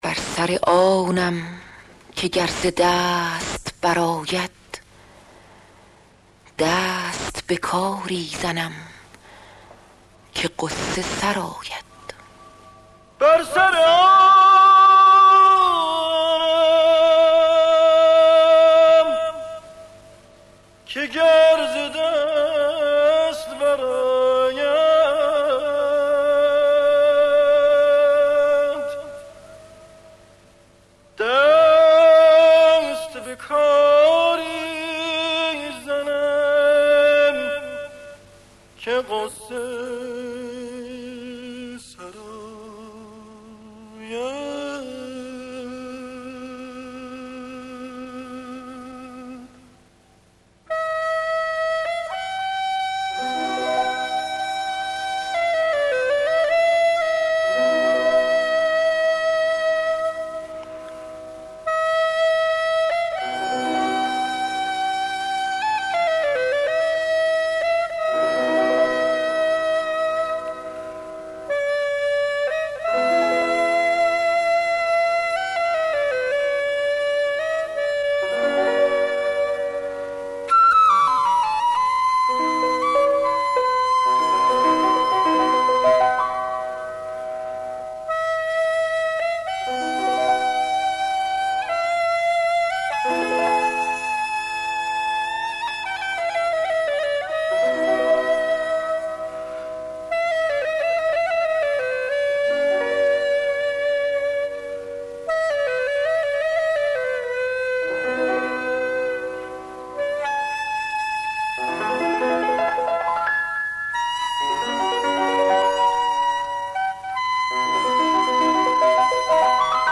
dastgahe mahour